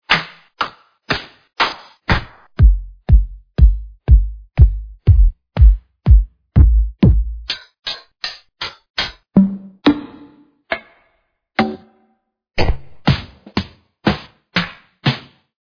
Studio-Drums bring 30 fresh and crispy sounds, ready to bring your tracks to perfection!
(the prelisten files are in a lower quality than the actual packs)
flph_studiodrums1_showcase.mp3